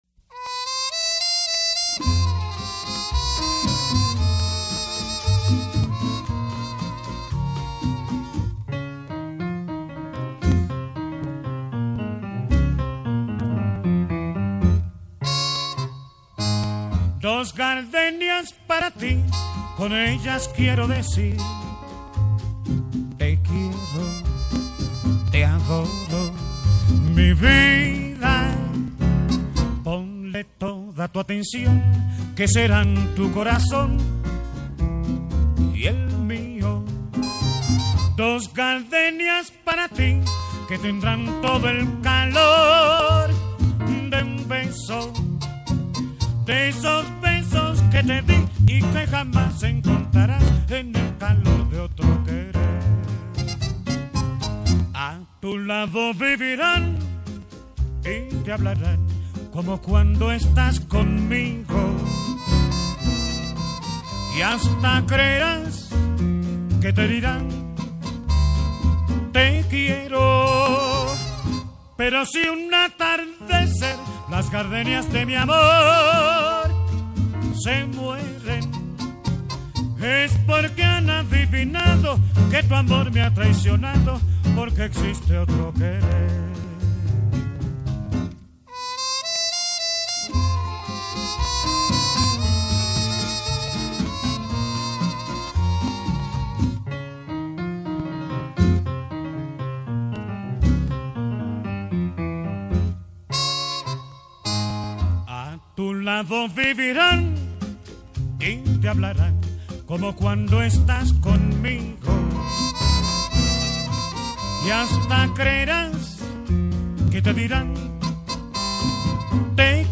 Mariachis